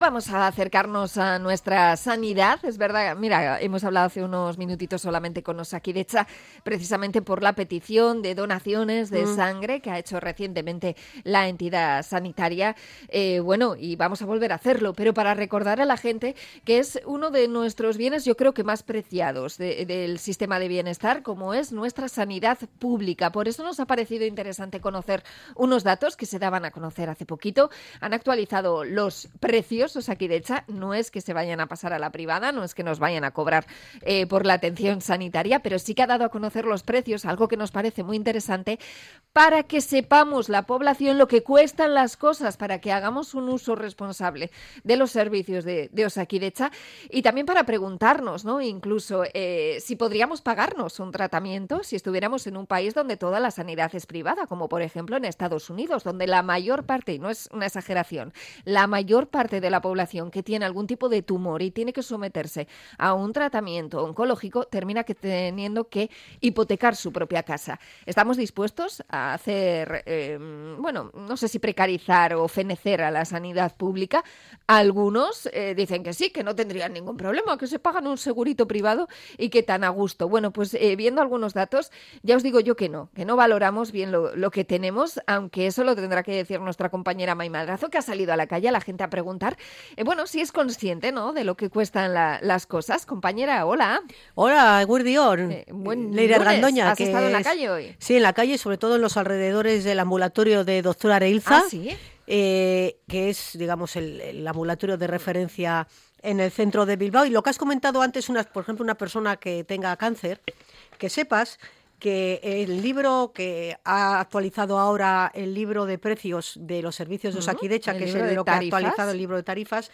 Encuesta callejera para saber si la gente sabemos el valor de los servicios públicos